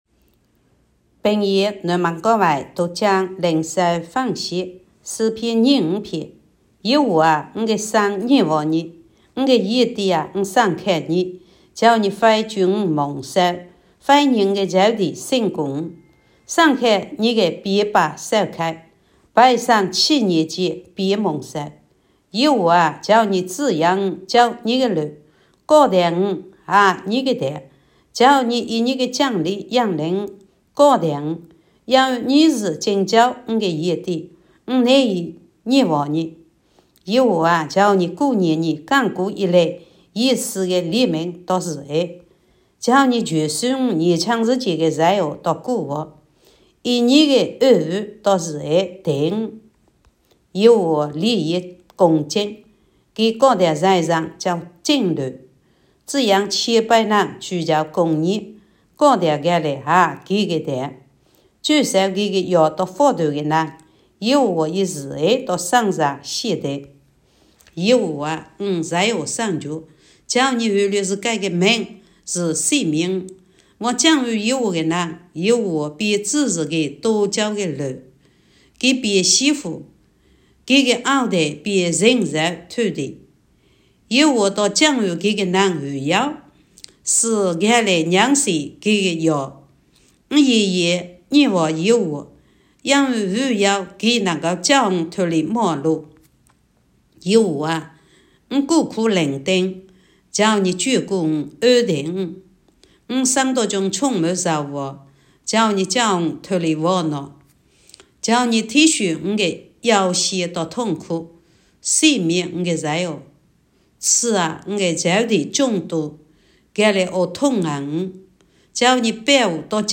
平阳话朗读——诗25